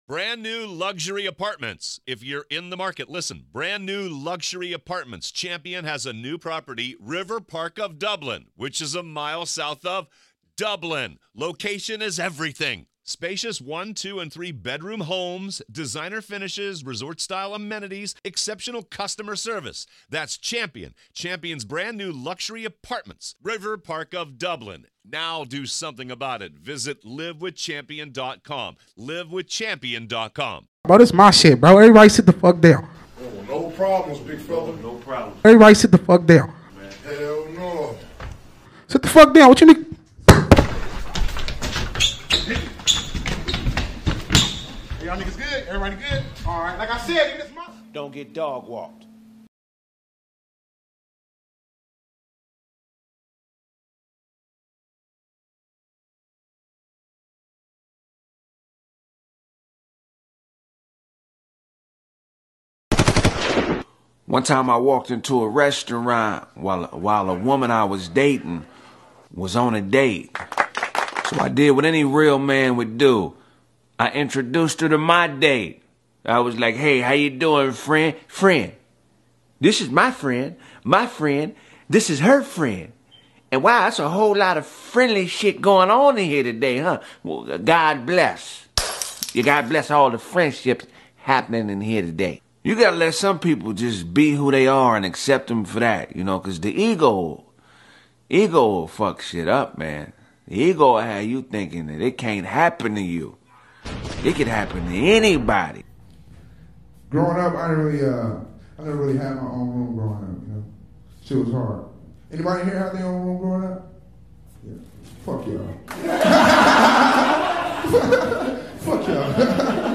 9/20/22 Call in Live